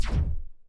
poly_explosion_small3.wav